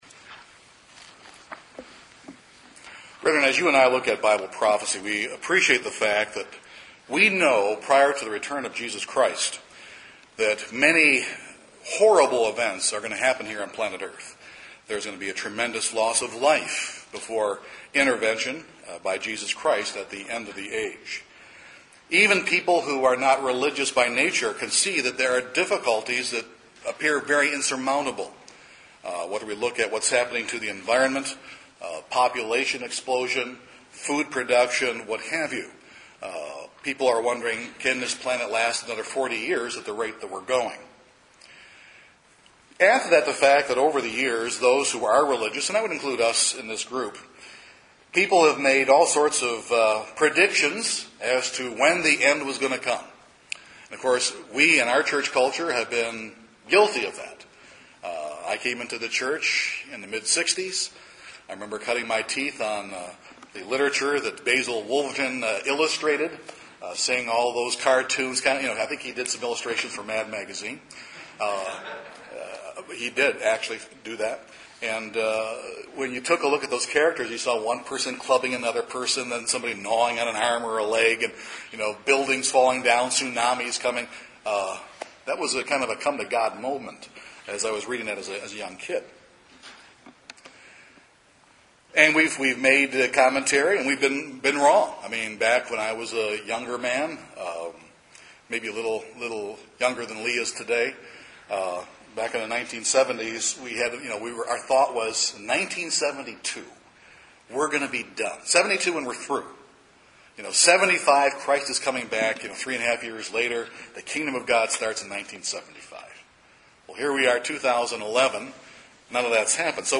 In this sermon we examine exactly what Jesus Christ, His apostles, and the prophets of old really said about the perilous days they referred to as the “time of the end” and what the signs would be that indicate we are at the “end of the age.”